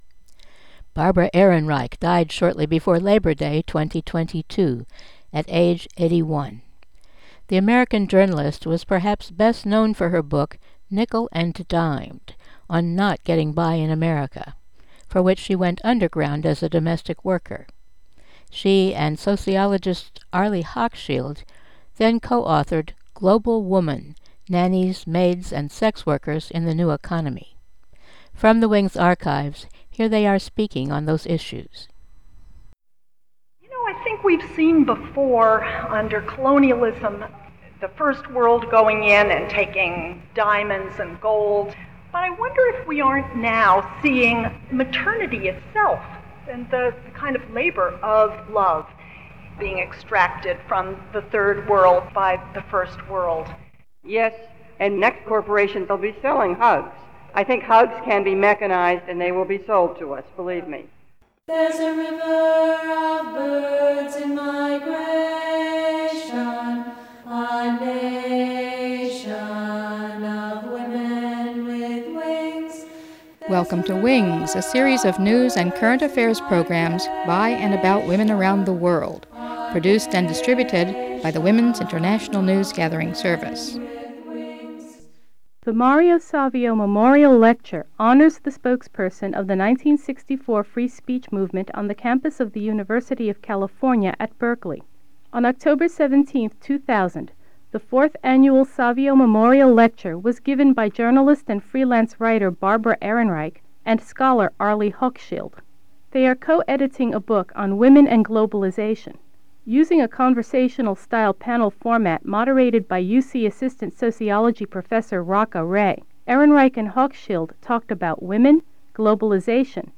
Barbara Ehrenreich and Arlie Hochschild in dialogue
Excerpts from the 4th Annual Mario Savio Free Speech lecture at Univ. of California Berkeley in the year 2000